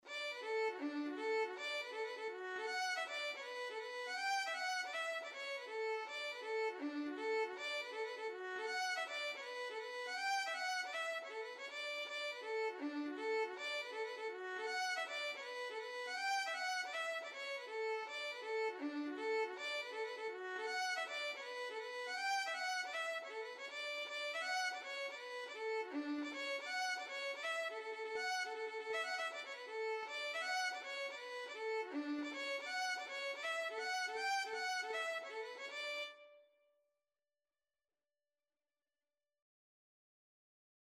Free Sheet music for Violin
D major (Sounding Pitch) (View more D major Music for Violin )
2/4 (View more 2/4 Music)
Violin  (View more Intermediate Violin Music)
Traditional (View more Traditional Violin Music)
Irish